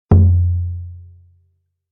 Surdo-4.mp3